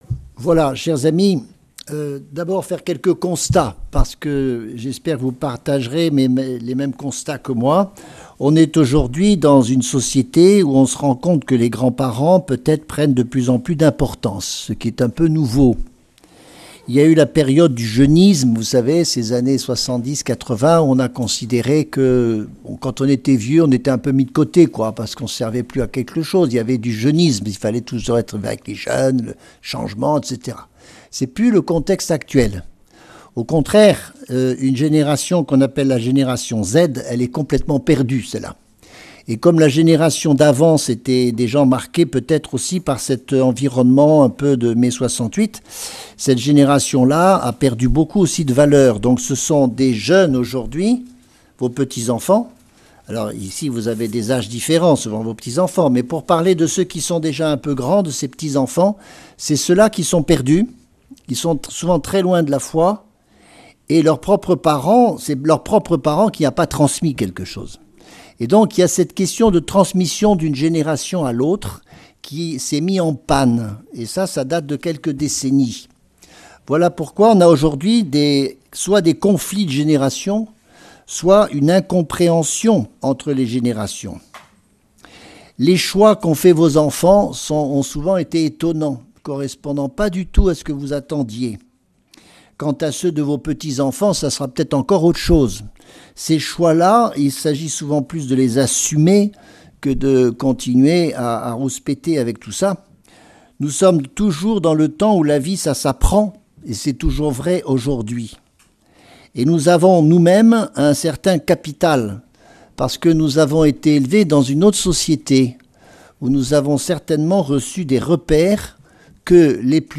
Lourdes, Pèlerinage avec la Cté des Béatitudes